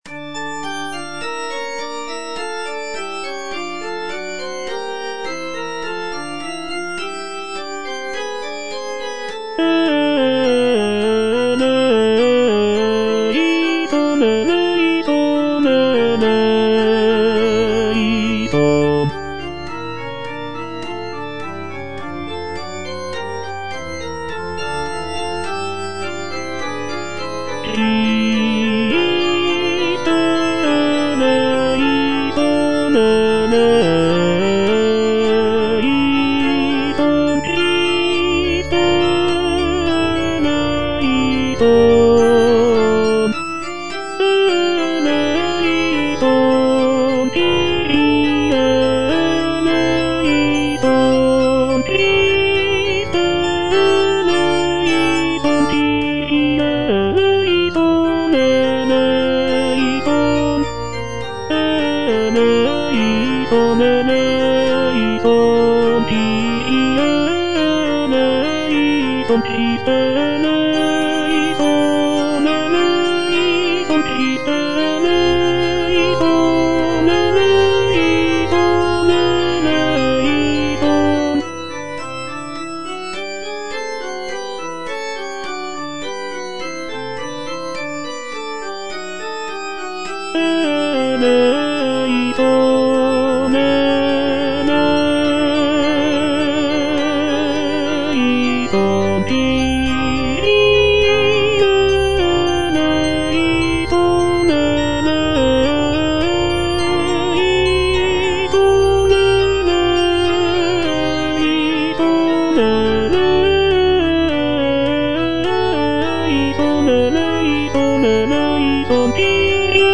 F. VON SUPPÈ - MISSA PRO DEFUNCTIS/REQUIEM Kyrie - Tenor (Voice with metronome) Ads stop: auto-stop